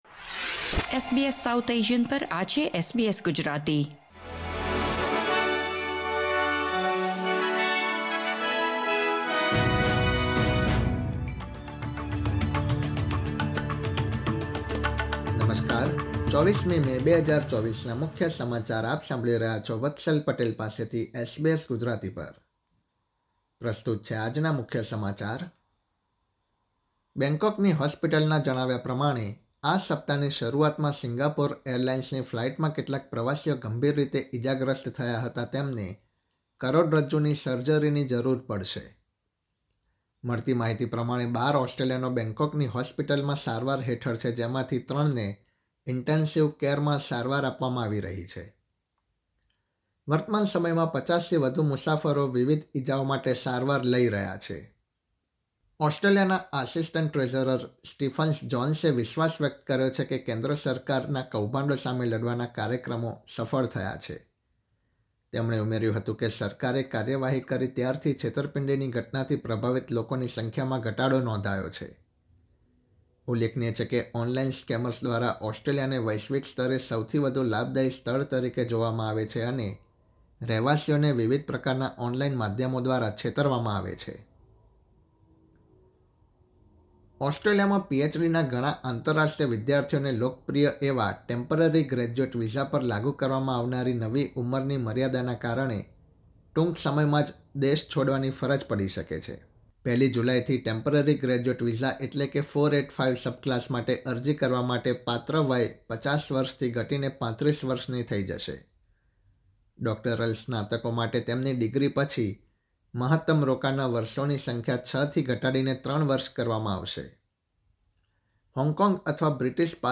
SBS Gujarati News Bulletin 24 May 2024